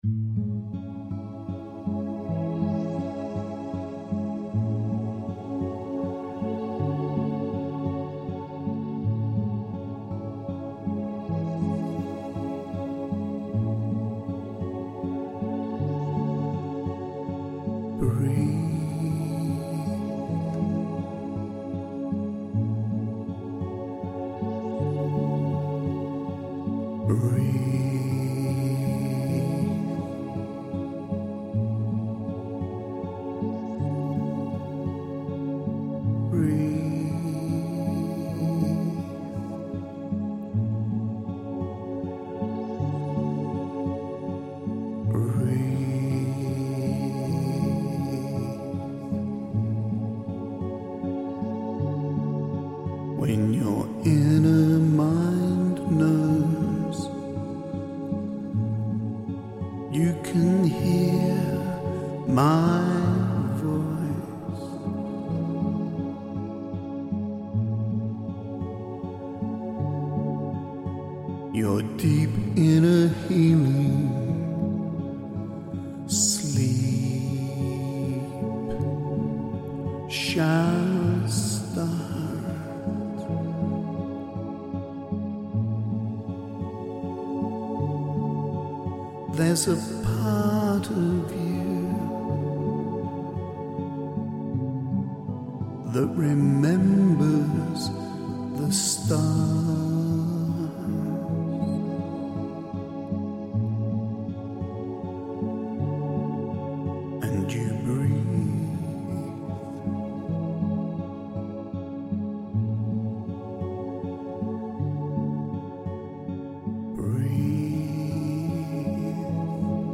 Breathe - a hypnosis song for deep healing sleep